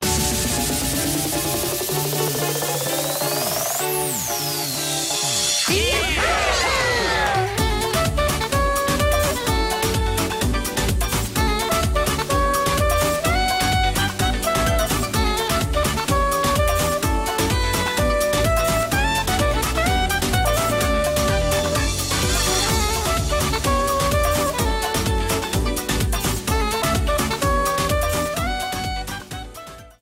The main menu theme
Copyrighted music sample